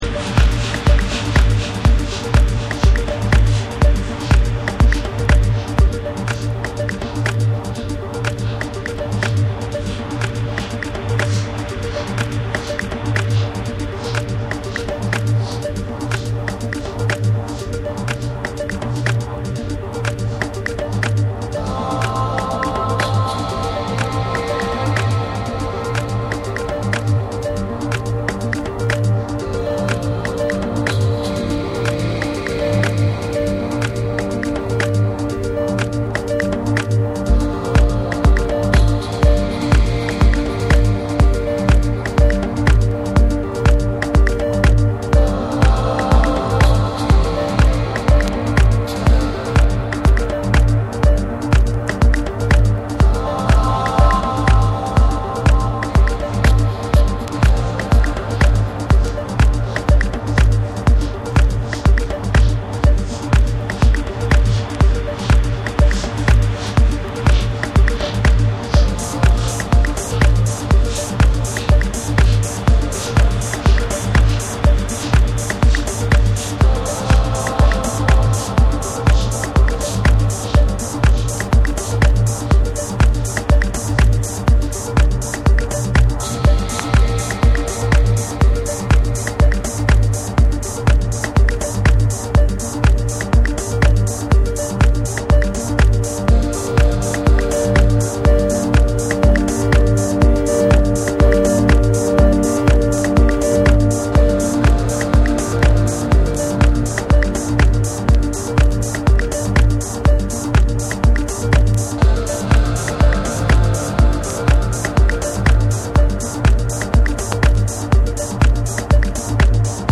スモーキーでしなやかなヴォーカルが心に染み入る浮遊感溢れるなテックハウスを披露するオリジナルの1。
TECHNO & HOUSE